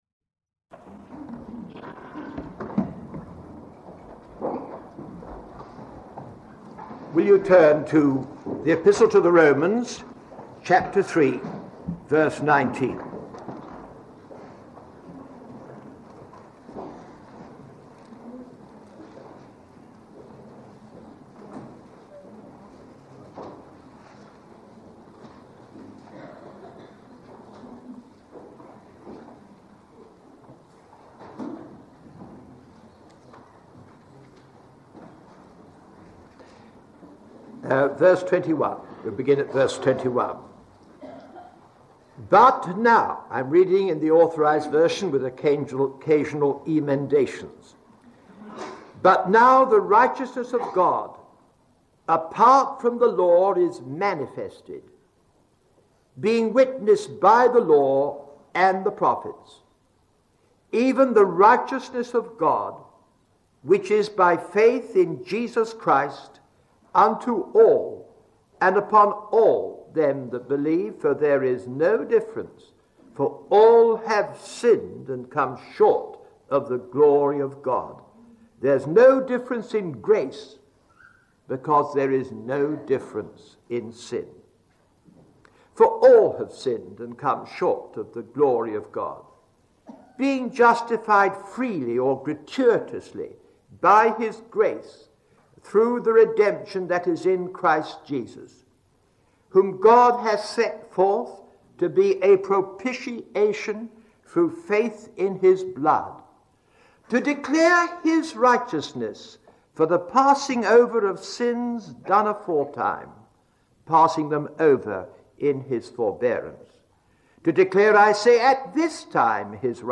In this sermon, the speaker emphasizes the importance of confessing one's wrongdoings and relying on the blood of Jesus for forgiveness. They discuss how being justified by God is not about proving oneself right, but rather acknowledging one's mistakes and seeking redemption through the cross.